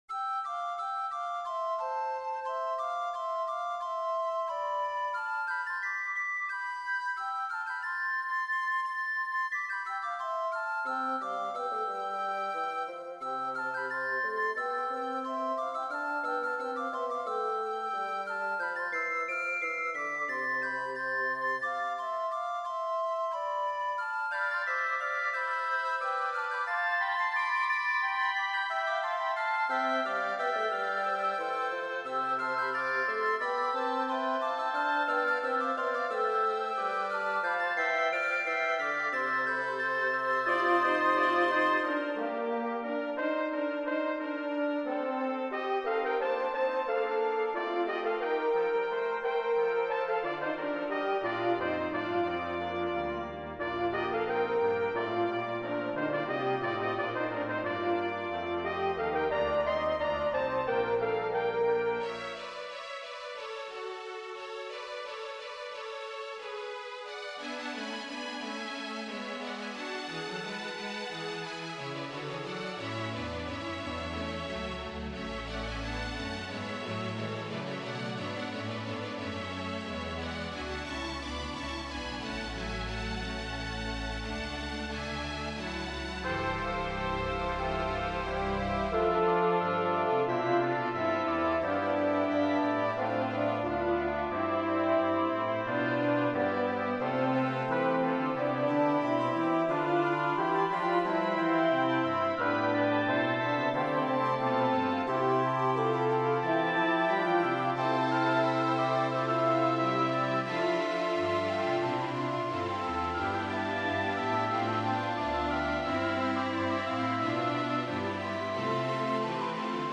Roy Howard has composed a new work for orchestra that matches the theme of a 2008 GPAC gallery exhibit.
Flute, Soprano Recorder, Alto Recorder, Bb Clarinet, Oboe, Bassoon
Trumpets 1,2; French Horn, Trombone, Tuba
Violins 1,2; Viola, Cello, Bass